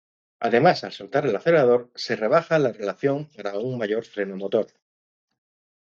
Read more Noun Verb rebajar to reduce to water down, dilute Read more Frequency 26k Hyphenated as re‧ba‧ja Pronounced as (IPA) /reˈbaxa/ Etymology Deverbal from rebajar.